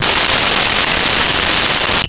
Cell Phone Ring 02
Cell Phone Ring 02.wav